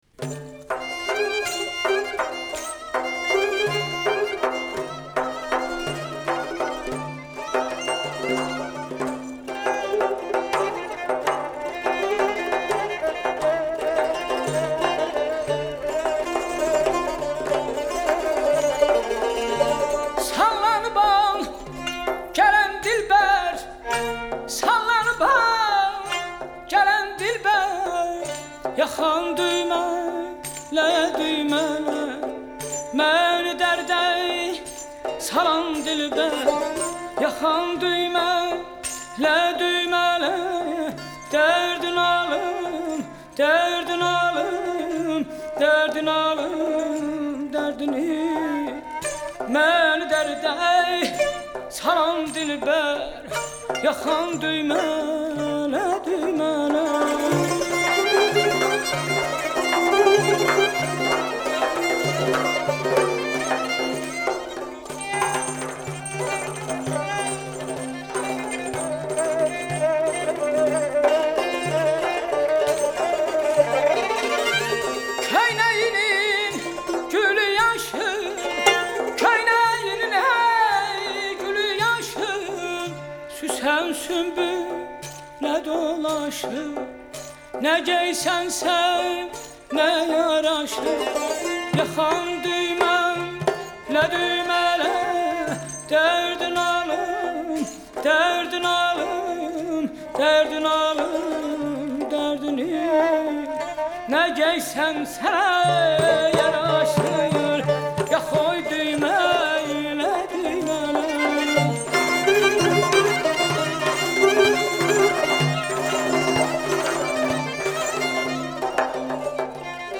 Music from Azerbaijan (Central Asia)
Mugham: Folk Music from Central Asia